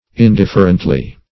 Indifferently \In*dif"fer*ent*ly\, adv.